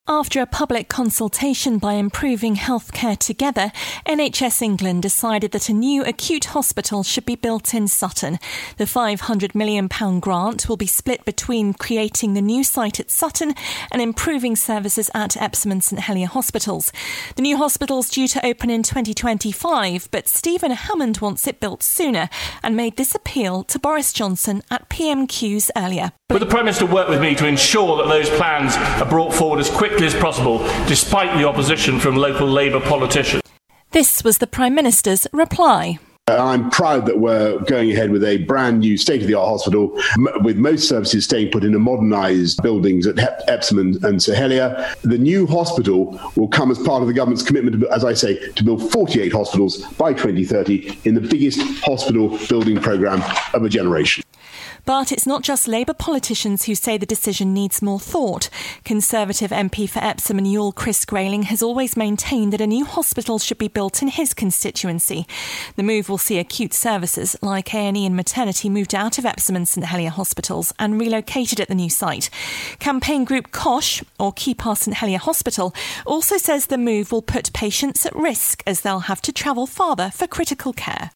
reports on PMQs